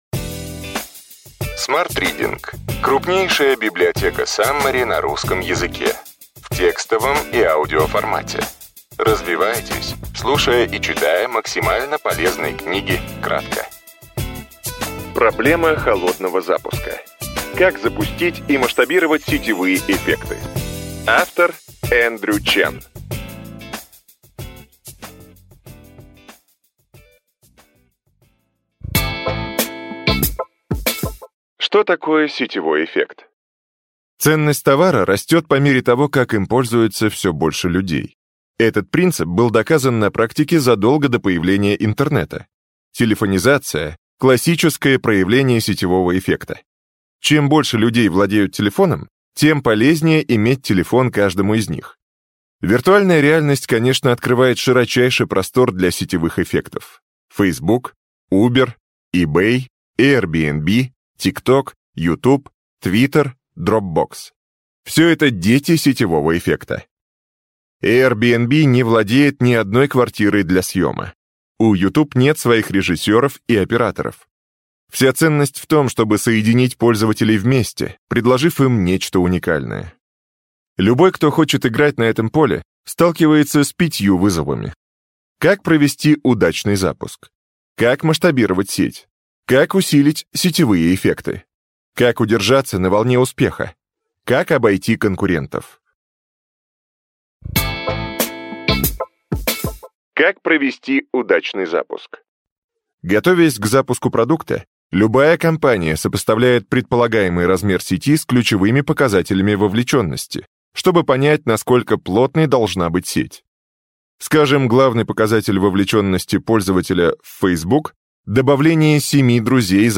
Аудиокнига Ключевые идеи книги: Проблема холодного запуска. Как запустить и масштабировать сетевые эффекты. Эндрю Чен | Библиотека аудиокниг